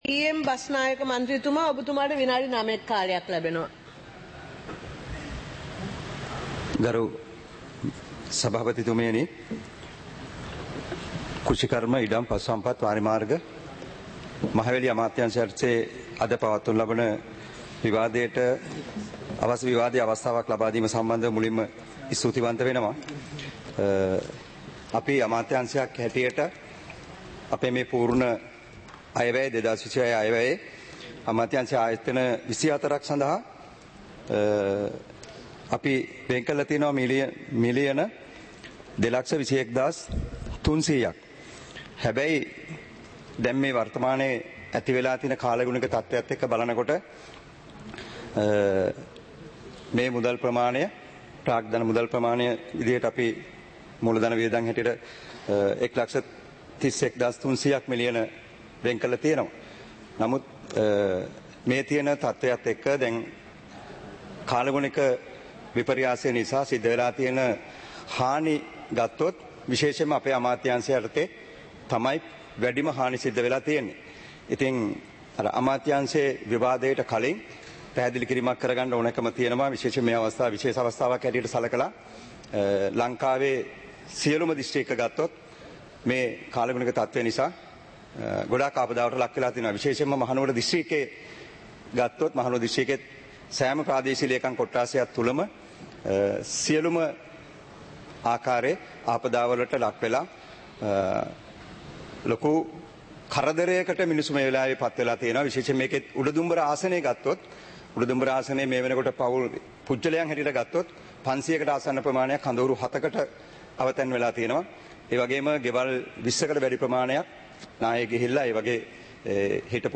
සභාවේ වැඩ කටයුතු (2025-11-27)